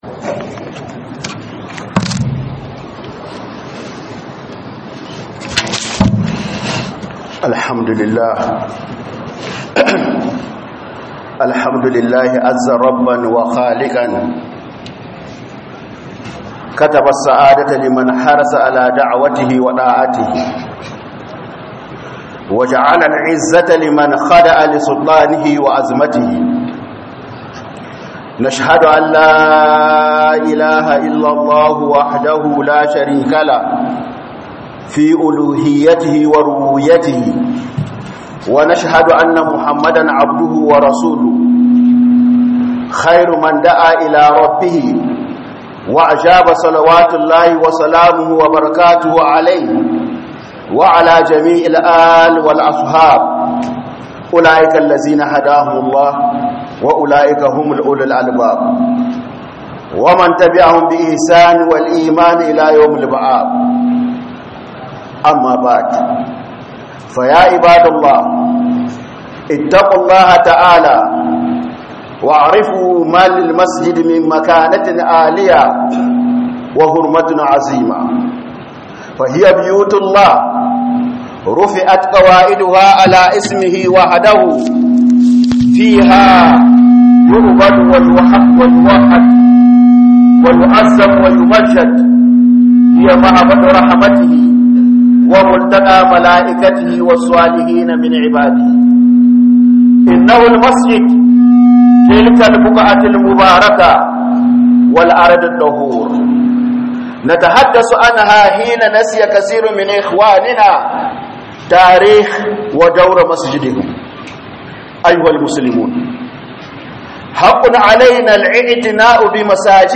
Huduba kan Gidajen Allah sun fi cancantar girma.